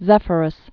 (zĕfər-əs)